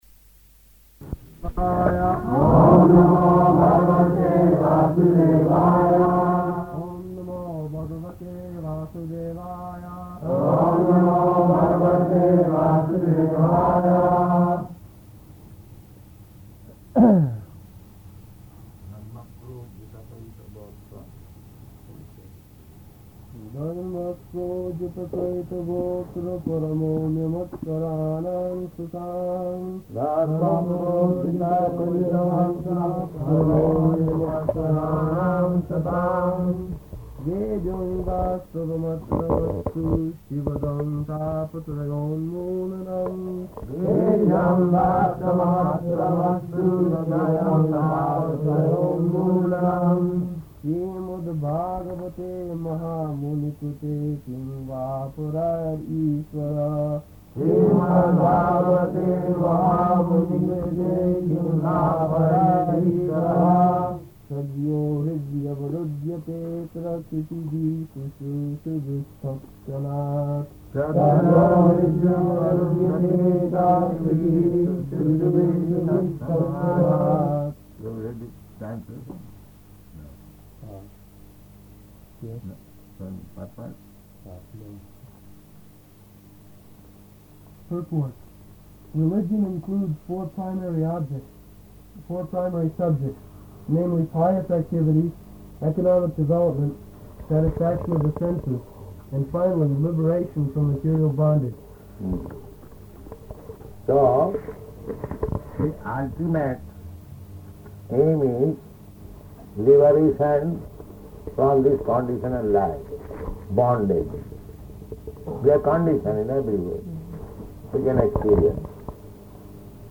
August 15th 1971 Location: London Audio file
[Prabhupāda and devotees repeat] [leads chanting of verse, etc.]